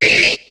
Cri de Gobou dans Pokémon HOME.